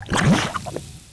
fs_slime.wav